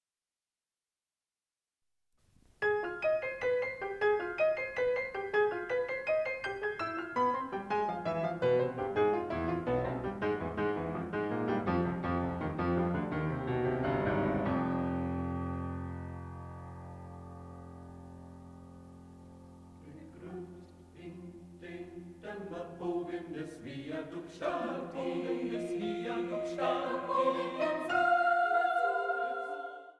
in Mitschnitten der Uraufführungen
4. Rezitativ